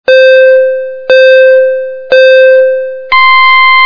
StartCountdown.mp3